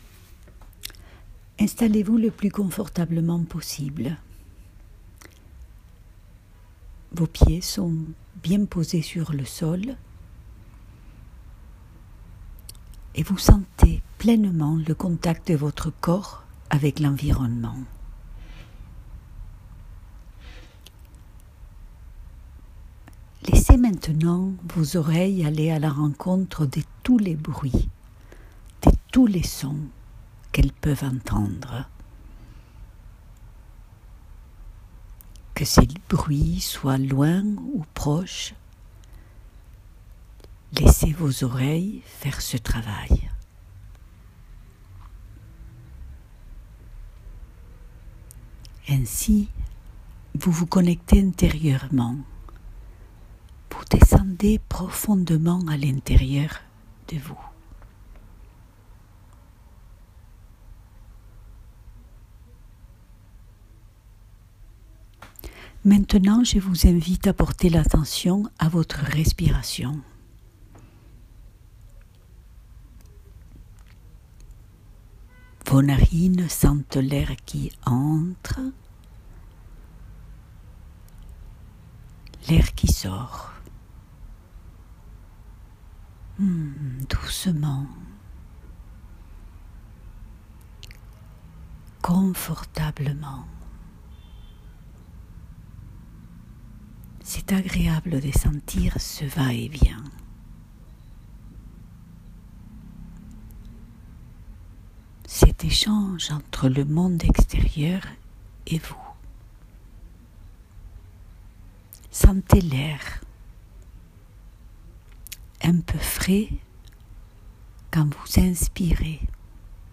J’ai enregistré quelques méditations guidées pour mes patients; mais j’invite tous ceux qui le souhaitent à s’en servir.